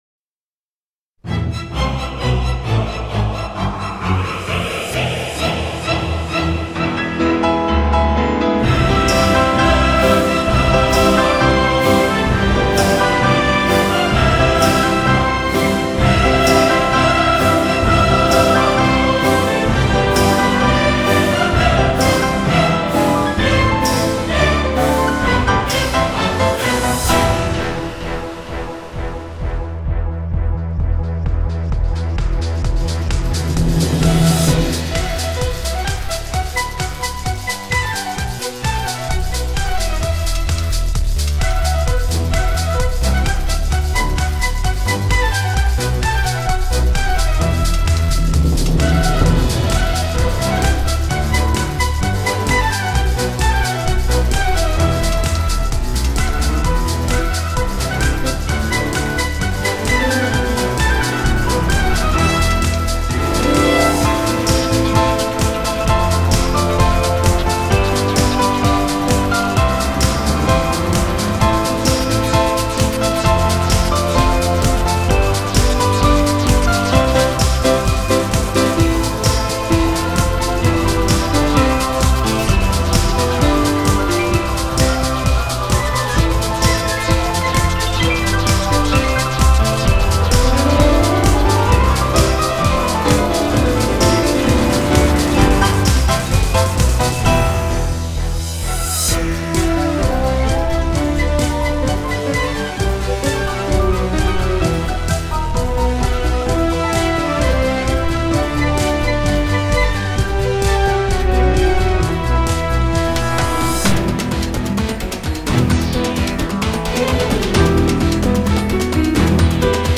变成一首活力洋溢、
动用120人合唱团
与60人大乐团之豪华气势版。